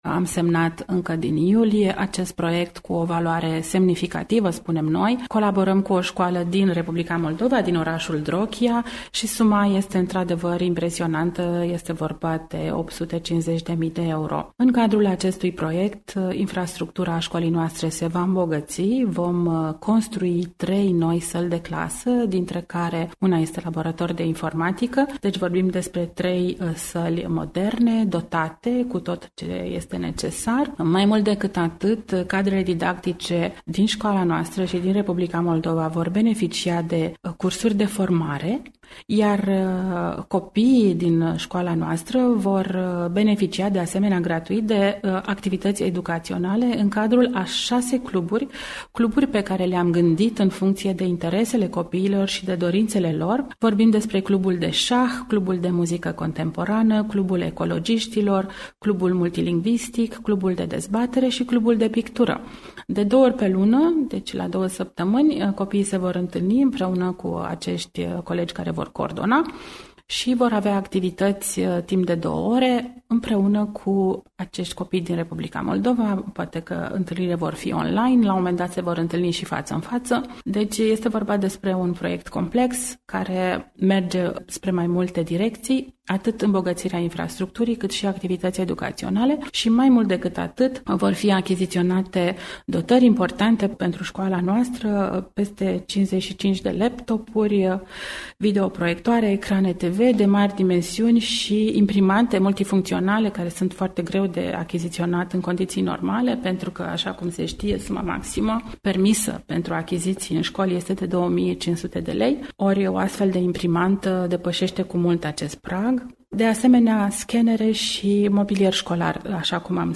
Varianta audio a interviului: